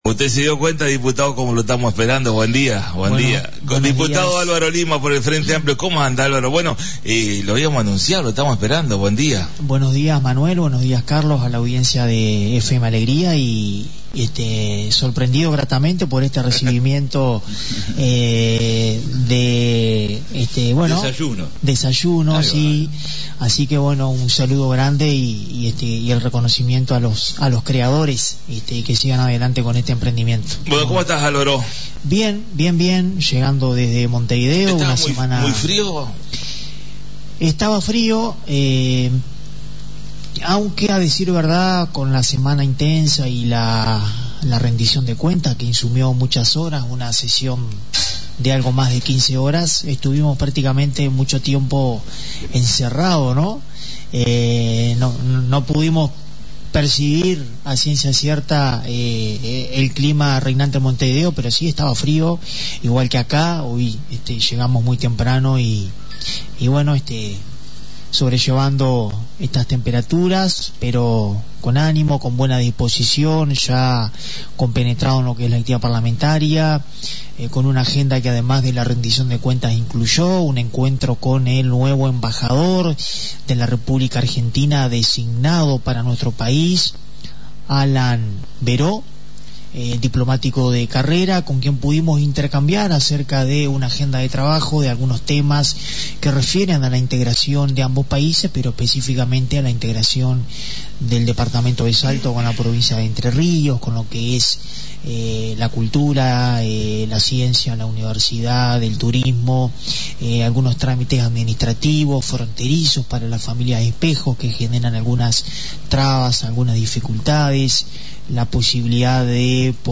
La visita a la Radio del Diputado por el Frente Amplio Dr Álvaro Lima, una linda charla, un poco de lo que dejó la derrota electoral y la actualidad en Diputados.